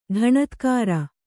♪ ḍhaṇatkāra